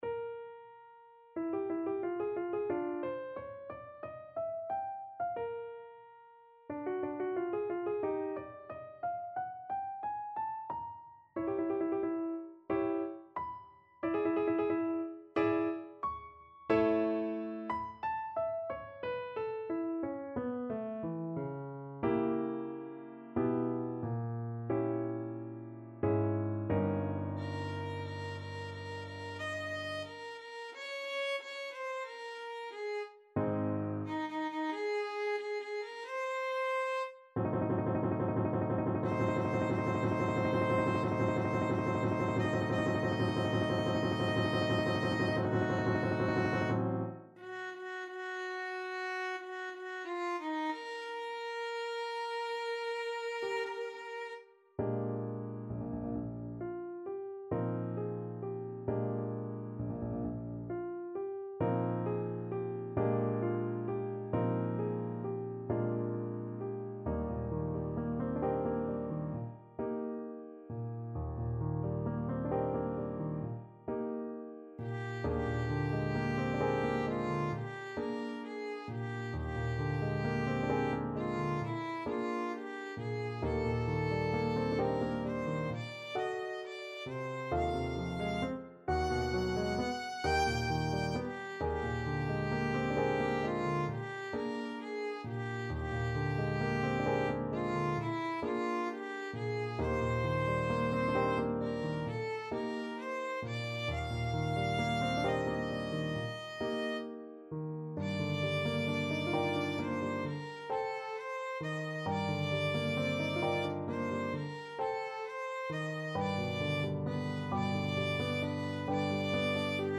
Violin version
Moderato =90
4/4 (View more 4/4 Music)
D5-B6
Classical (View more Classical Violin Music)